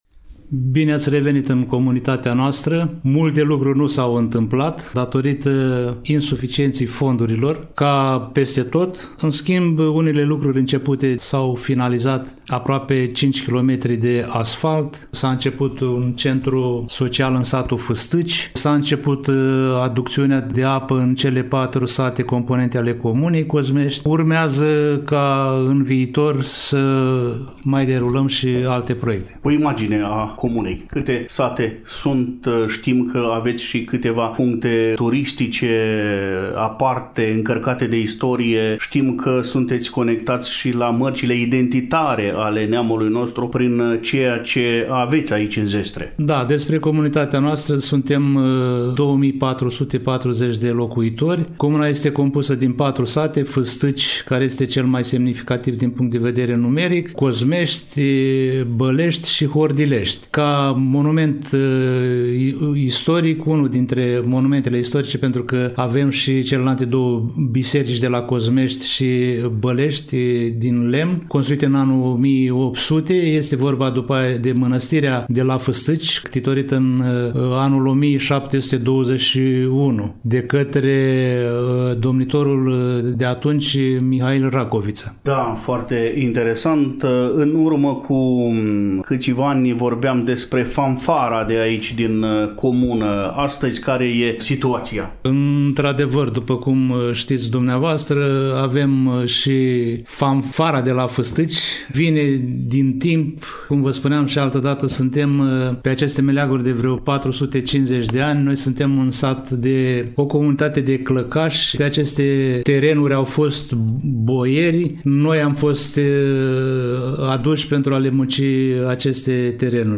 Primul interlocutor al emisiunii este domnul Hristache Sima, edilul șef al comunei Cozmești.